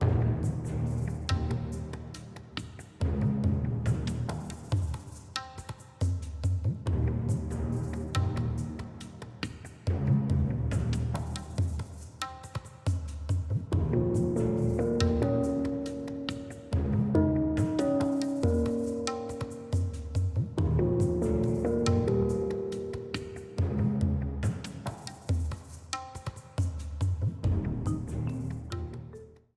A red streamer theme
Ripped from the game
clipped to 30 seconds and applied fade-out